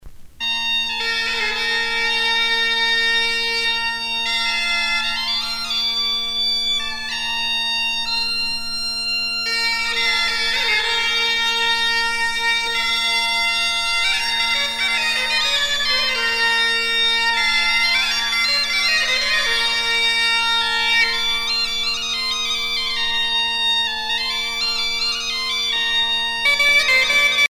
danse : bal (Bretagne)
Genre laisse
Pièce musicale éditée